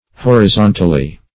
Horizontally \Hor`i*zon"tal*ly\, adv.